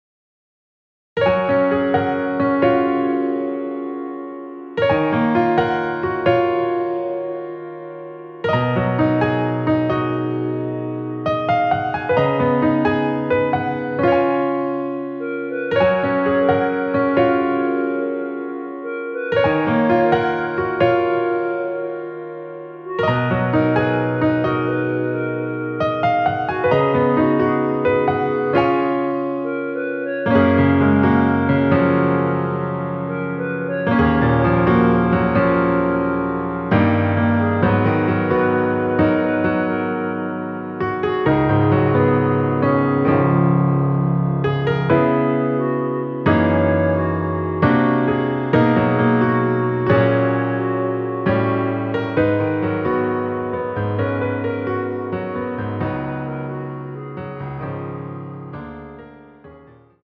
원키에서(-4)내린 멜로디 포함된 MR입니다.
F#
앞부분30초, 뒷부분30초씩 편집해서 올려 드리고 있습니다.
(멜로디 MR)은 가이드 멜로디가 포함된 MR 입니다.